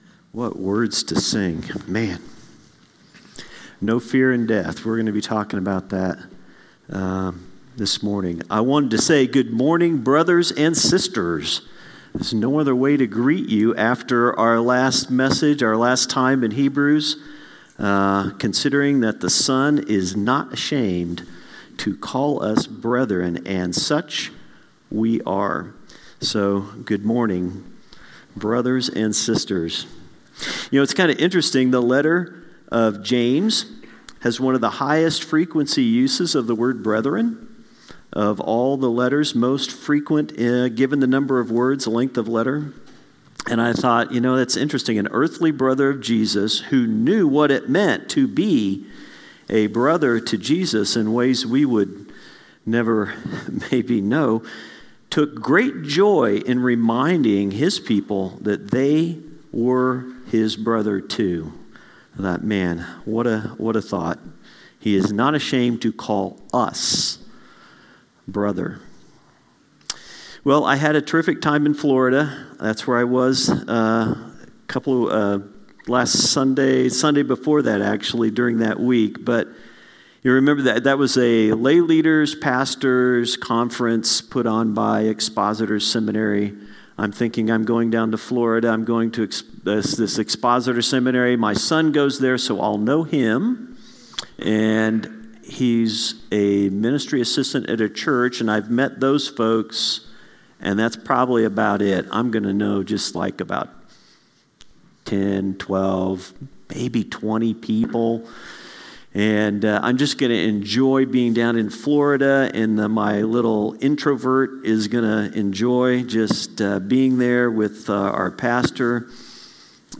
A message from the series "Hebrews."